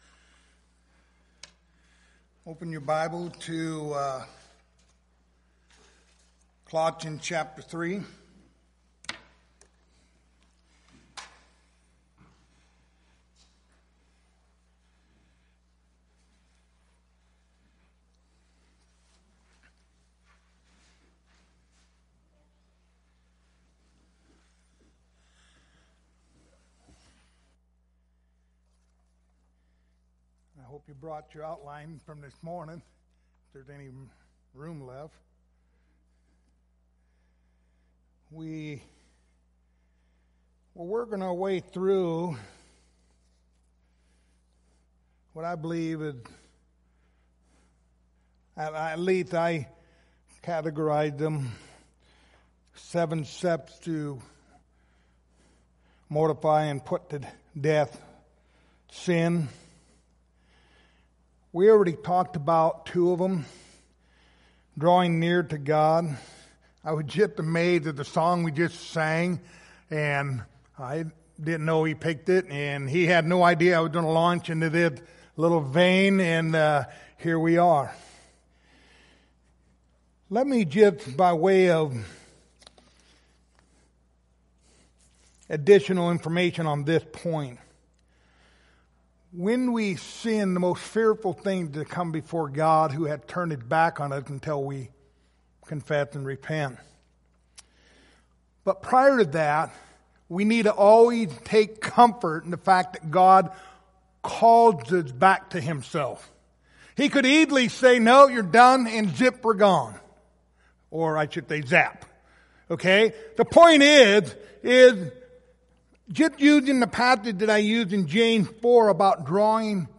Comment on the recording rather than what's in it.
Passage: Colossians 3:5-7 Service Type: Sunday Evening